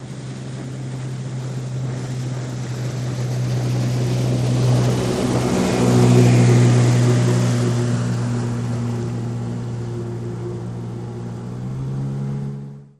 50 hp Evinrude Boat Pass By, Slow